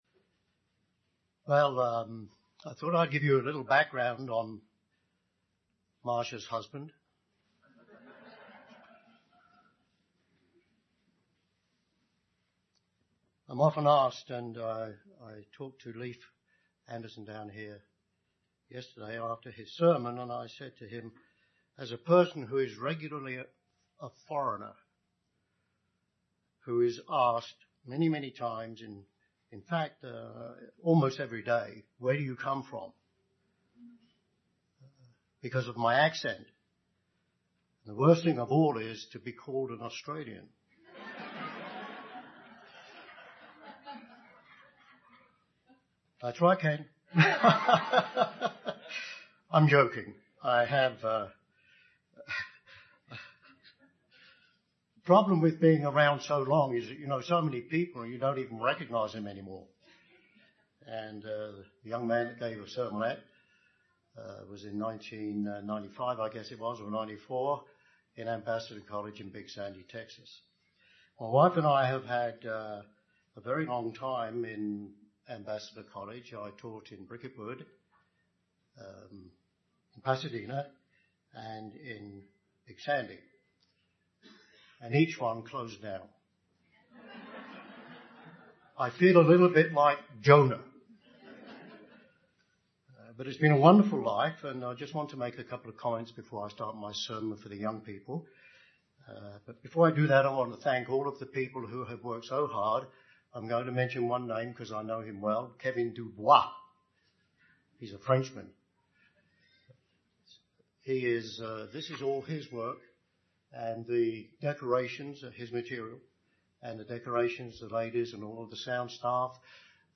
This sermon was given at the Steamboat Springs, Colorado 2016 Feast site.